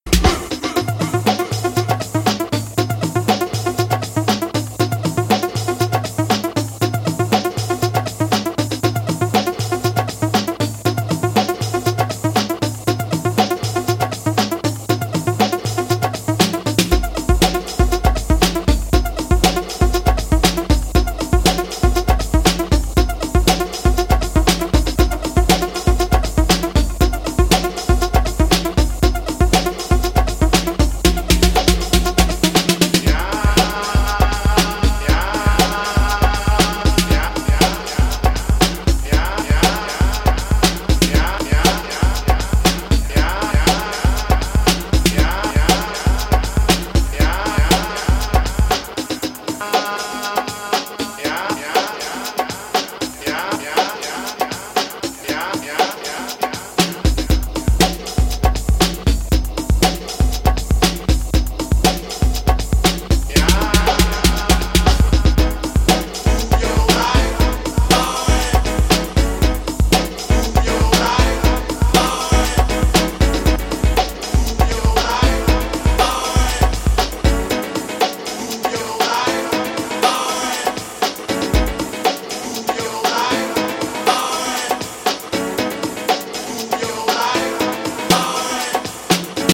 Classic ealry house tracks!